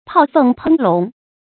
炮凤烹龙 páo fèng pēng lóng
炮凤烹龙发音